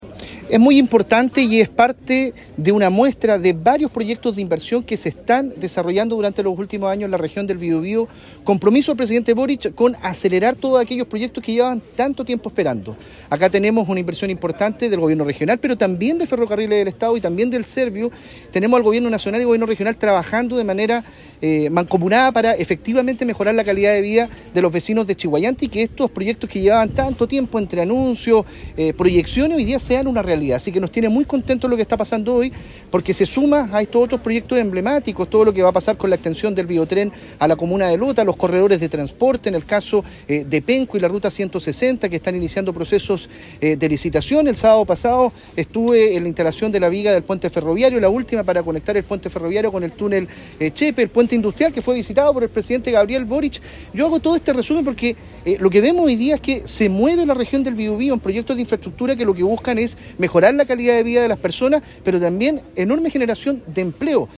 El inicio de obras contó con la presencia de autoridades regionales, quienes concordaron en destacar la importancia de la iniciativa que busca mejorar la conectividad urbana de la comuna, así como también potenciar la seguridad vial en el entorno de la nueva infraestructura.
En tanto, el delegado presidencial regional del Biobío, Eduardo Pacheco, valoró las mejoras en infraestructura ferroviaria.